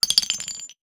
metahunt/weapon_ammo_drop_18.wav at 84a4c88435ec9cf2ad6630cab57ea299670b57e2
weapon_ammo_drop_18.wav